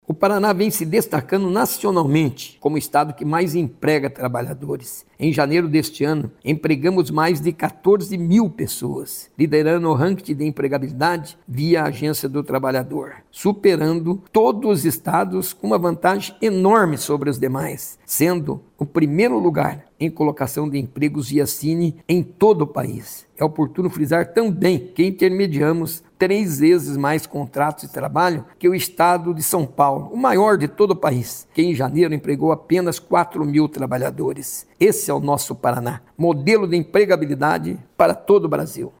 Sonora do secretário do Trabalho, Qualificação e Renda, Mauro Moraes, sobre a Rede Sine do Paraná fechar janeiro com mais de 14 mil empregos intermediados